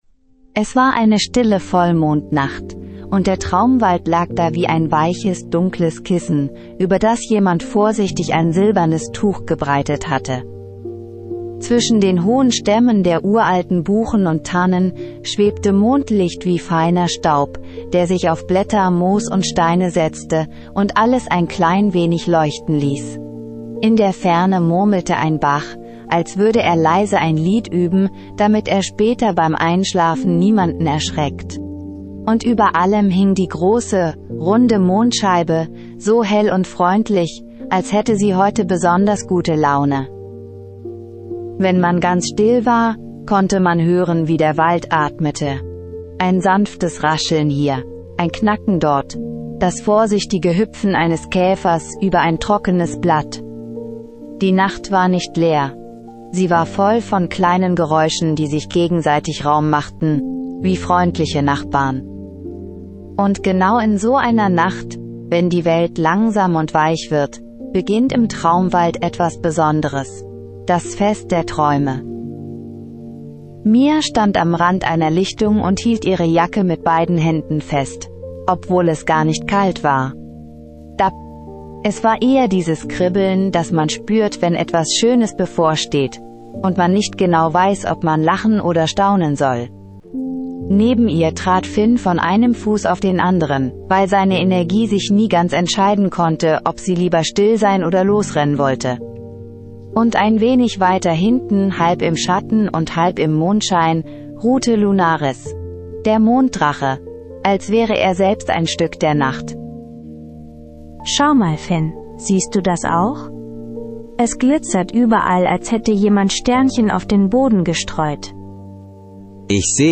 Magisches Einschlaf-Hörspiel für Kinder